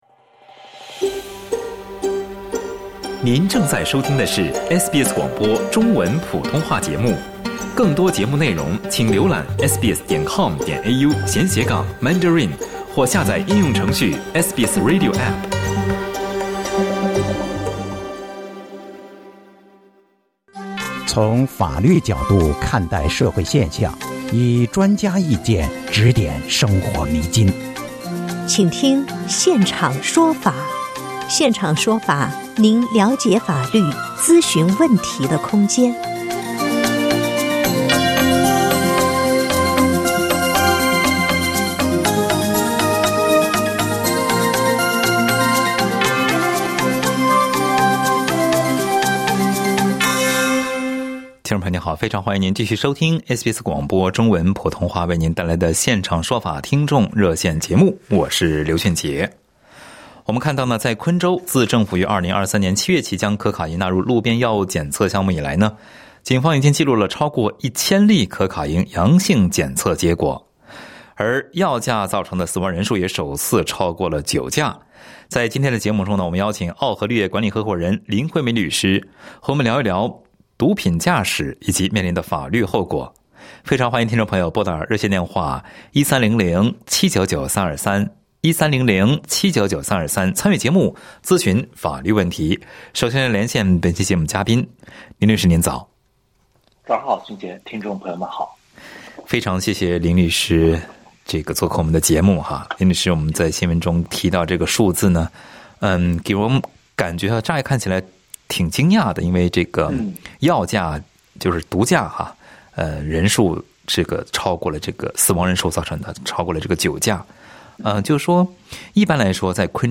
在本期《现场说法》热线节目中